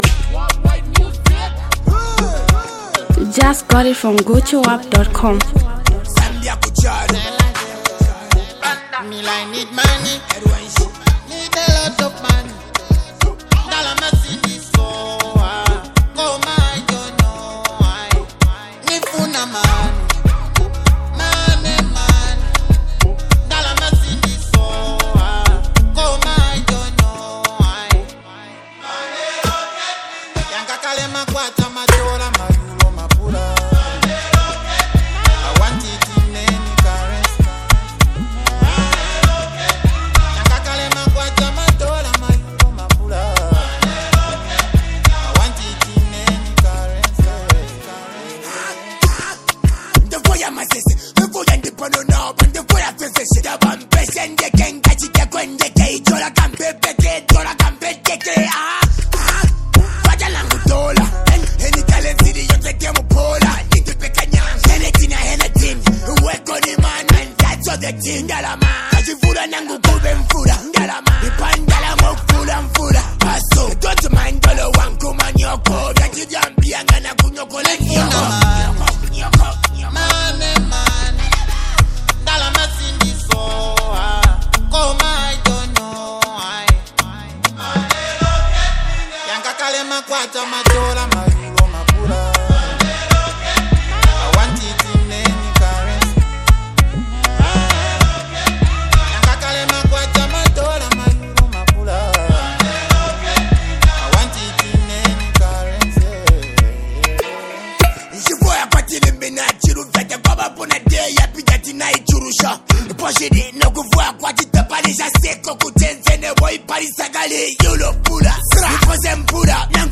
rap skills
melodic flow